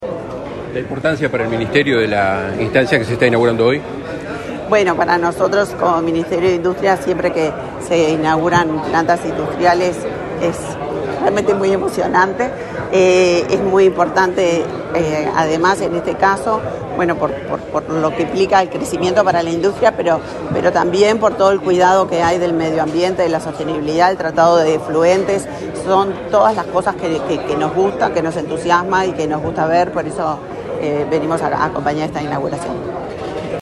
Declaraciones a la prensa de la ministra de Industria, Energía y Minería, Elisa Facio
Declaraciones a la prensa de la ministra de Industria, Energía y Minería, Elisa Facio 13/06/2024 Compartir Facebook X Copiar enlace WhatsApp LinkedIn Con la presencia del presidente de la República, Luis Lacalle Pou, fue inauguarada, este 13 de junio, la fábrica de procesamiento de limones San Miguel, en Paysandú. Tras el evento, la ministra de Industria, Energía y Mineria, Elisa Facio, realizó declaraciones a la prensa.